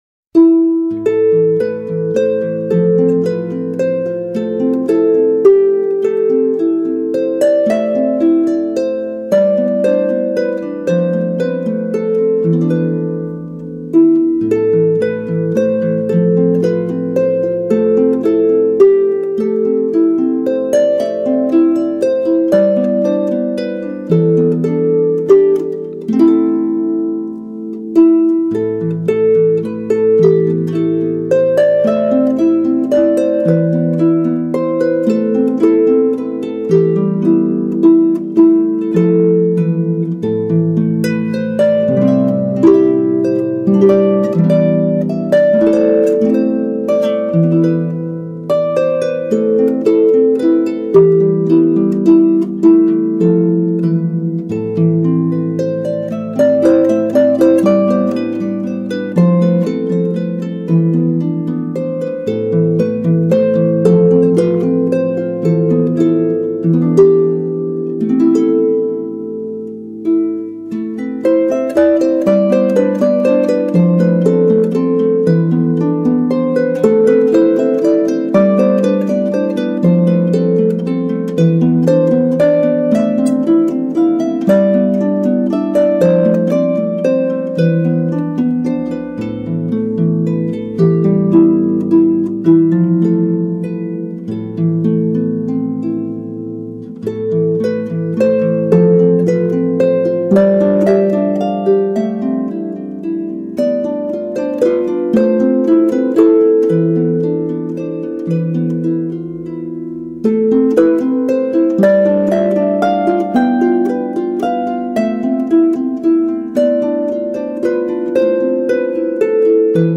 LOWER INTERMEDIATE, ALL HARPS.